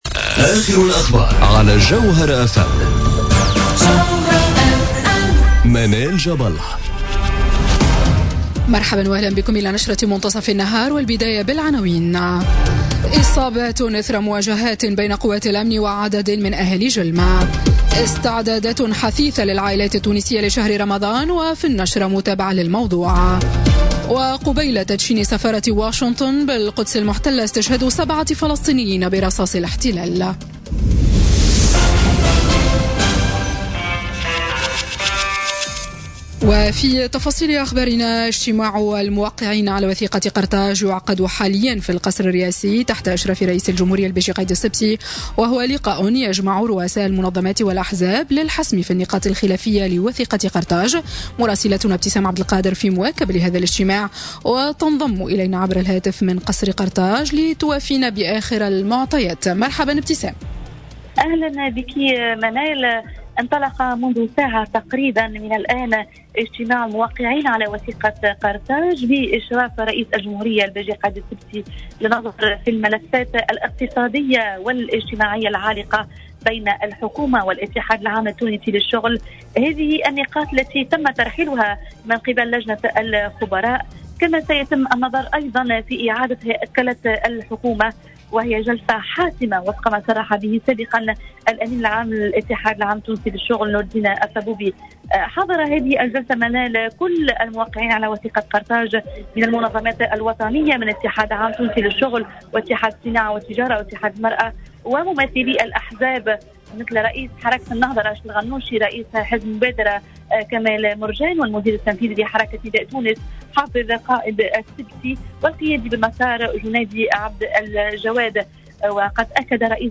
نشرة أخبار منتصف النهار ليوم الإثنين 14 ماي 2018